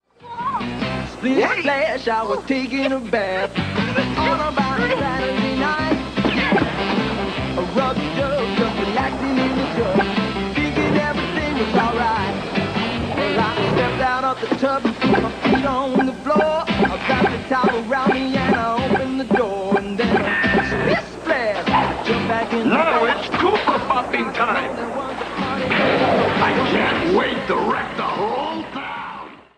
Short music sample of a cover song